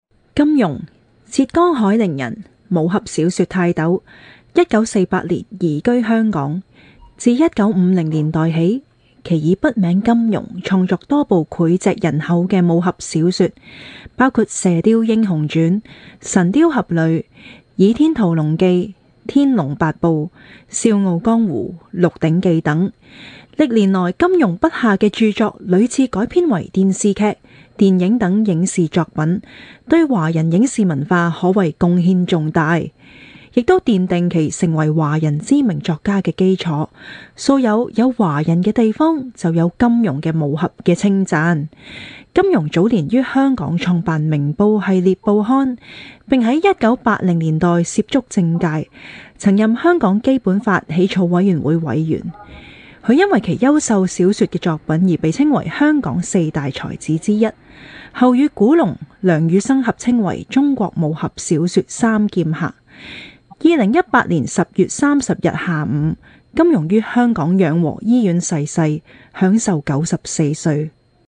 港式粤语配音
• 女粤03 香港粤语港式粤语女声 人物介绍正式 沉稳|娓娓道来|科技感|积极向上|时尚活力|神秘性感|调性走心|亲切甜美|感人煽情|素人